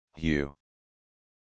Audio Pronunciation of Hew